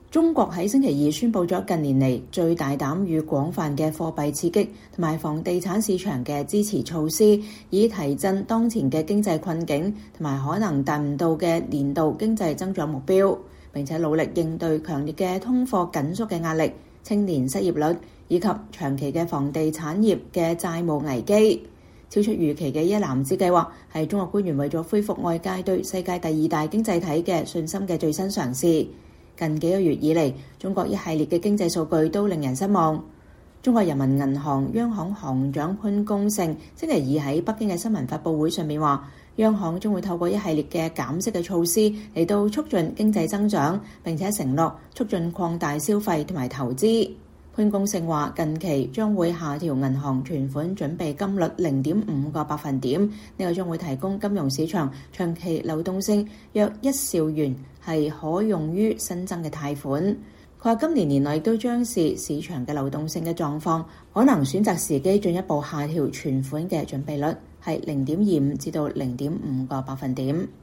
中國人民銀行行長潘功勝在北京的新聞發布會上宣布了近年來最大膽與廣泛的貨幣刺激和房地產市場支持措施。